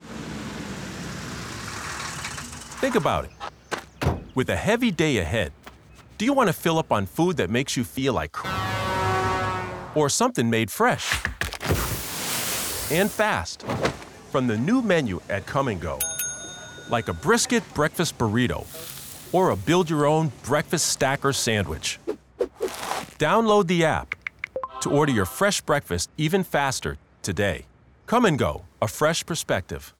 29-07-22 Kum & Go Construction Worker 30s Radio Full Mix - V1 Alt.wav
My voice is distinctive, rich and full of resonance, while sounding familiar.